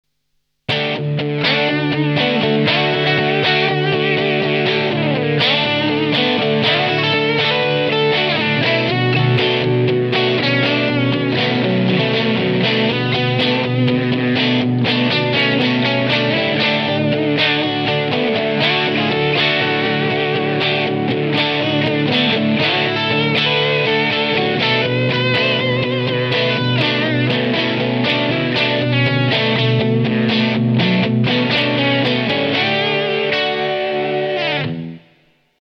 G：Combat Custom 3S / PU：Single
アンプ・サウンドを活かしながらナチュラルで滑らかなオーバードライブ感と
弾き心地の良いサステインを加える隠し味的なオーバードライブ・セッティング。
サステインと滑らかさを加えたチューブODサウンド。
Overdrive setting with a subtle touch of natural smooth overdrive and
comfortable sustain.